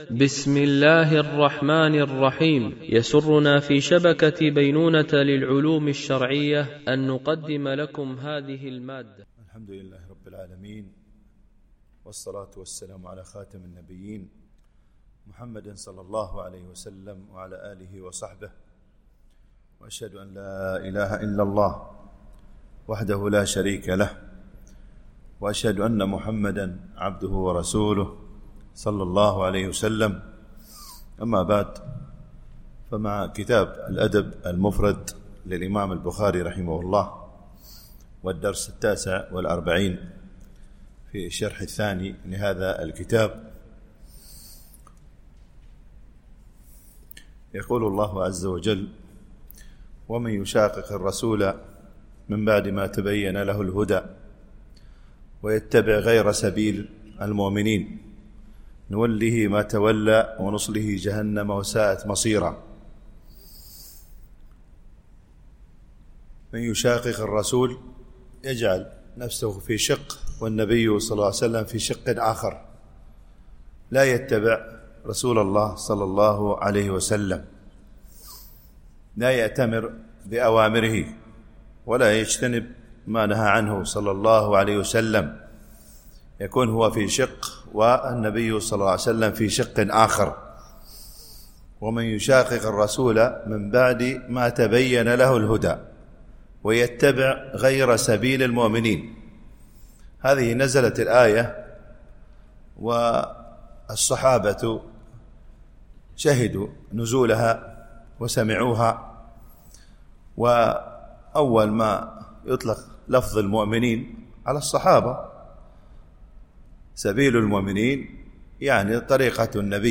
الشرح الثاني للأدب المفرد للبخاري - الدرس 49 ( الحديث 208- 212 )